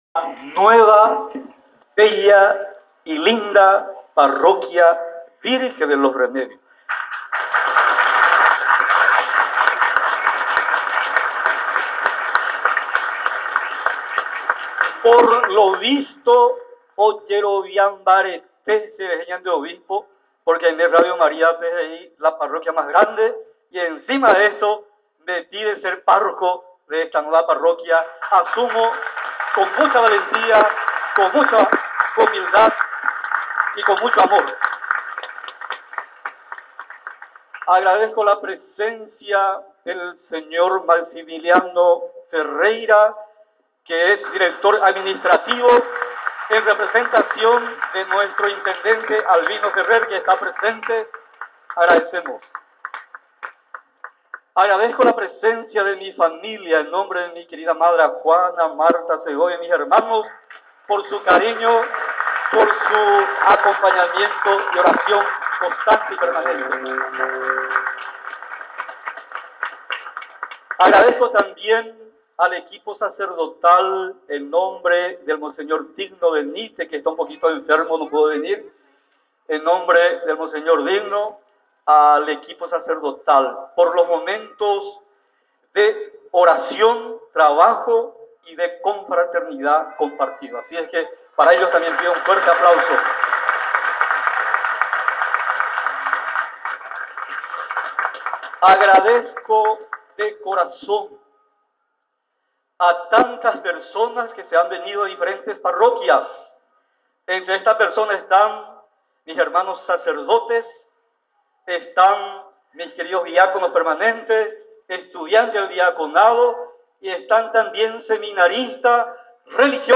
La misa estuvo celebrada por el Obispo de la Diócesis de San Lorenzo Monseñor Sebelio Peralta acompañado por sacerdotes diáconos y religiosas de la Parroquia de San Lorenzo.